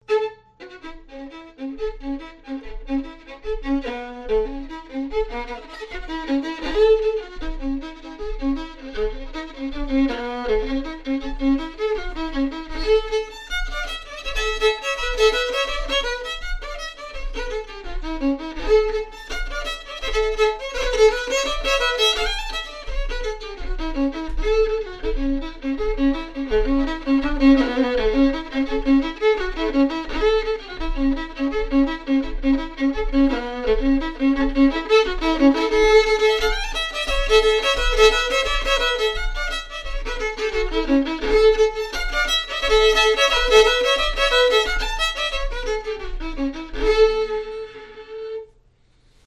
Note Accompanied by: unacc